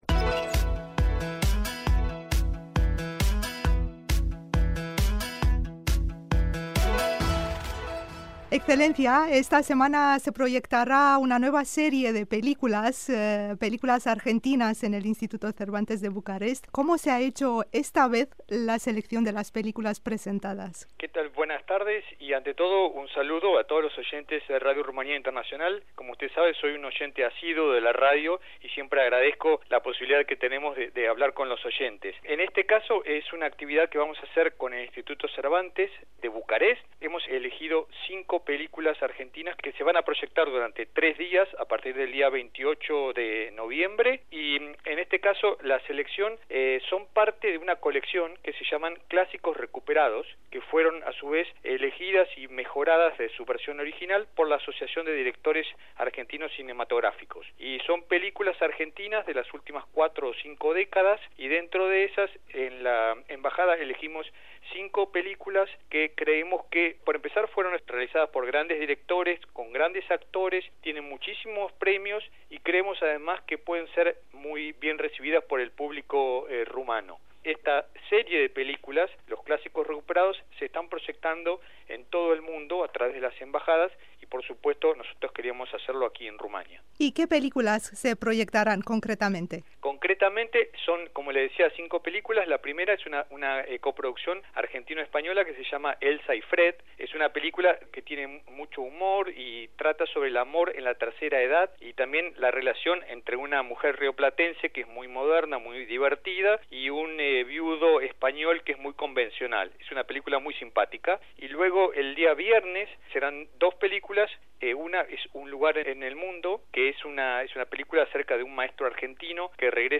S. E. el Sr. Alejandro Poffo, Embajador de Argentina en Rumanía, nos ofrece más detalles sobre este evento organizado en la capital de nuestro país: